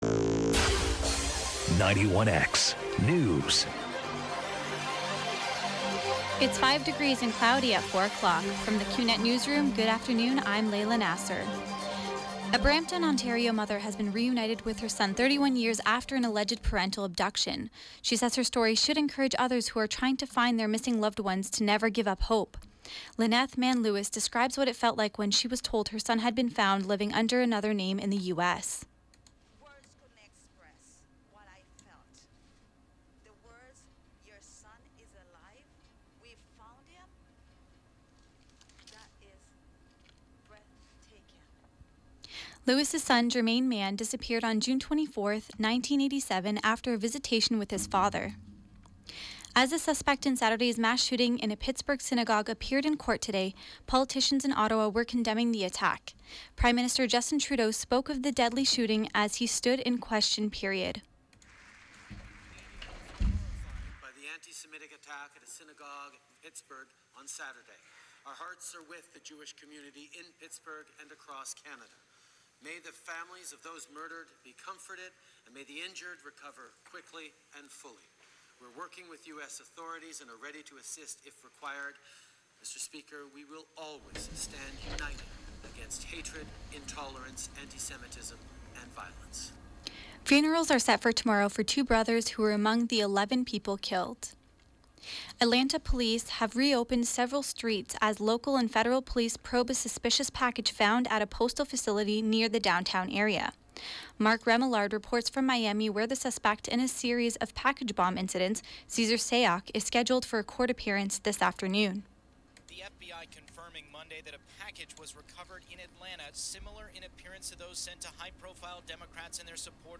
91X Newscast: Monday, Oct. 29, 2018